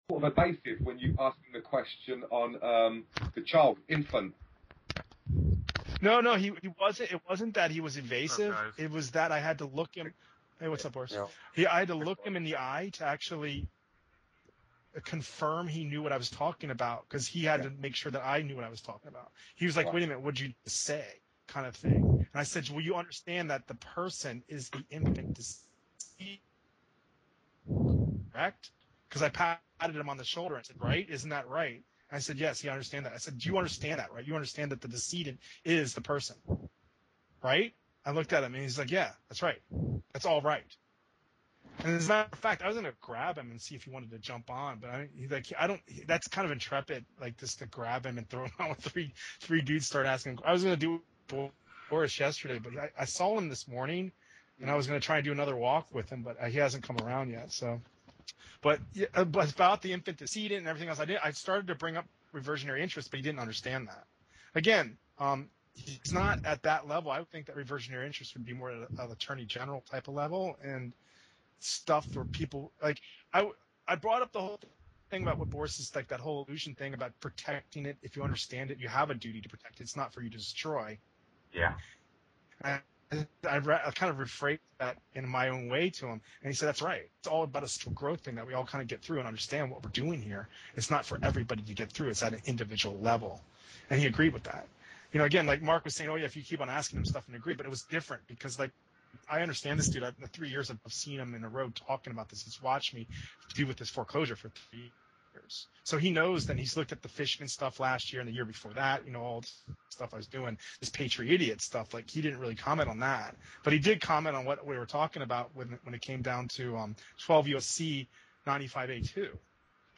Conversation With DC Lawyer
Q and A Discussion With Lawyer
Convo_with_DC_Lawyer_1and2_edit.ogg